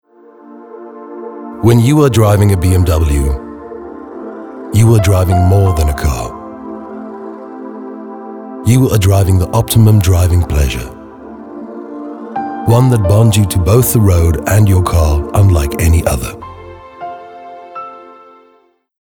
authoritative, Deep, raspy
BMW Soft Sell Luxury